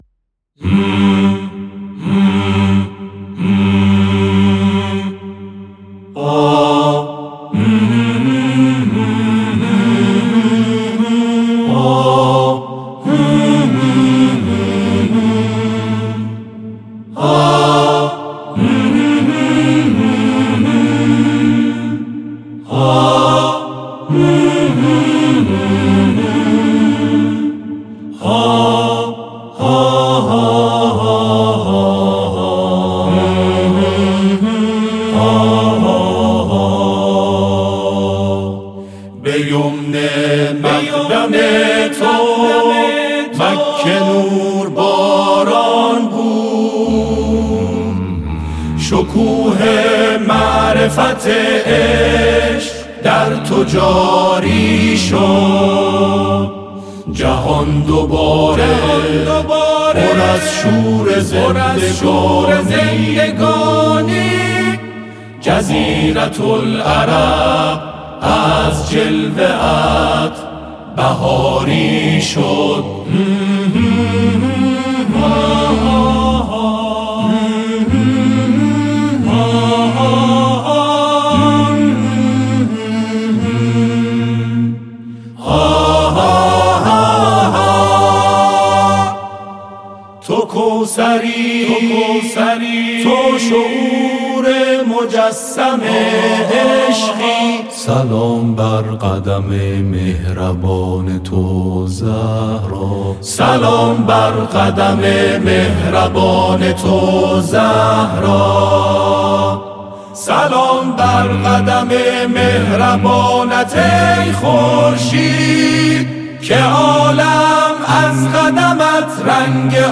براساس فرم موسیقی آکاپلا